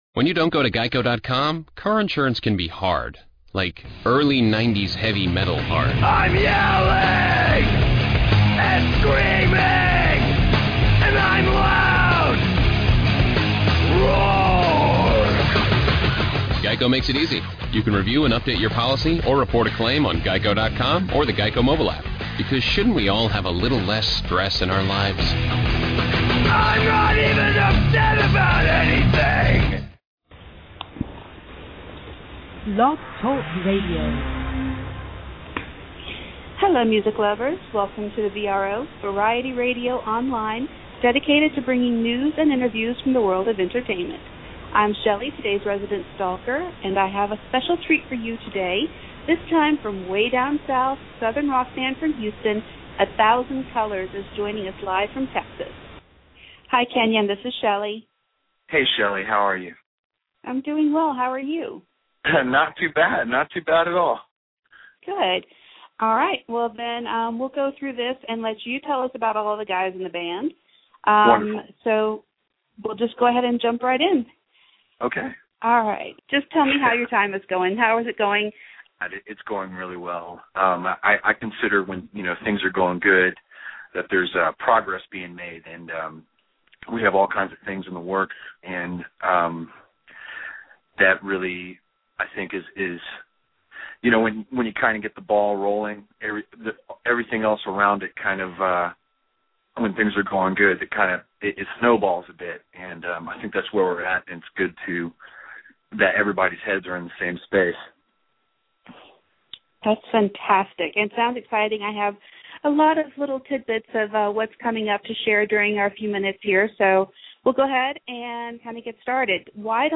Interviews / A Thousand Colours